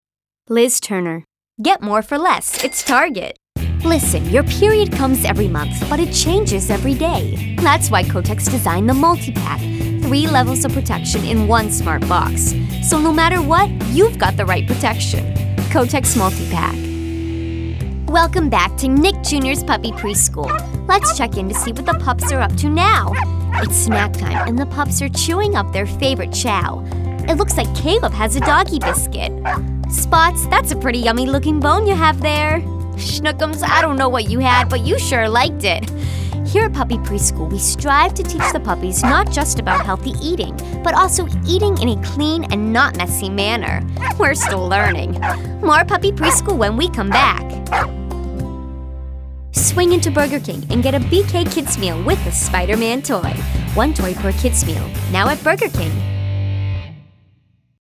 Demos
Boston, New England, New York, Italian, French.
Teen
Young Adult